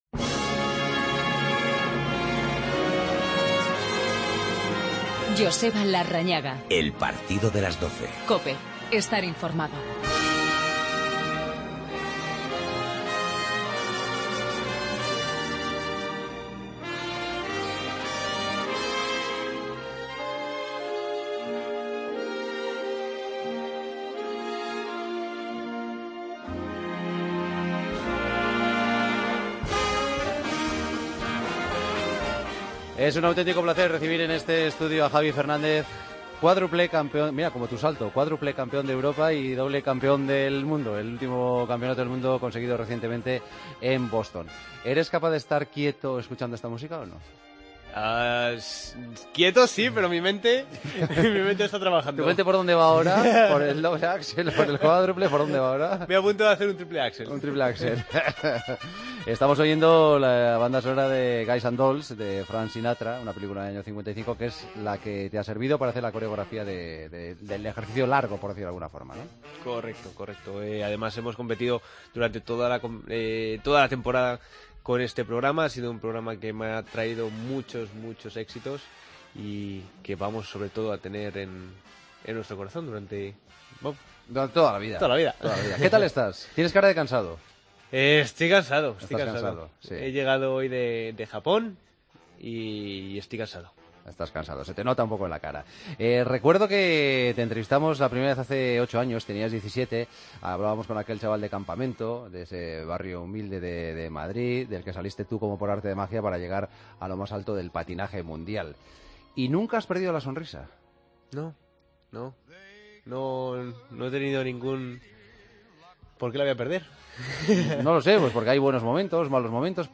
Queremos que este deporte no termine aquí.Patinar con una sonrisa ayuda a conseguir las cosas", explicó en los estudios de COPE, el campeón del mundo de patinaje artístico, Javier Fernández.